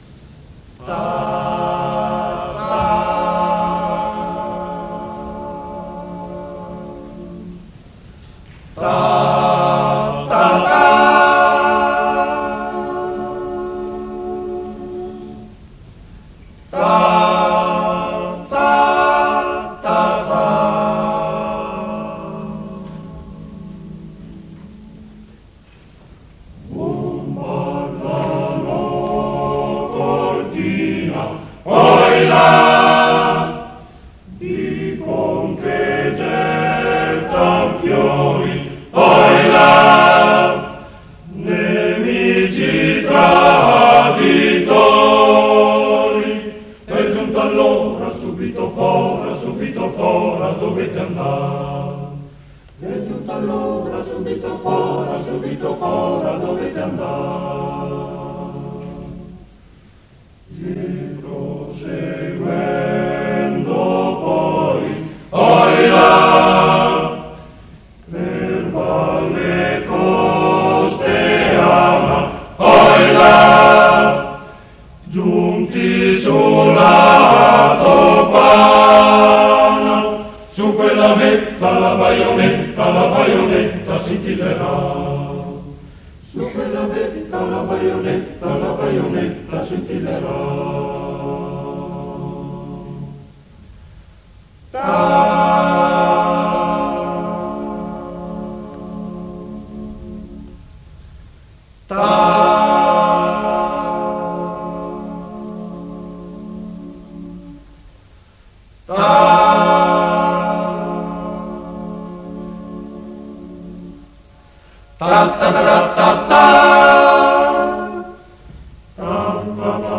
Torino, Conservatorio G. Verdi, 8 dicembre 1956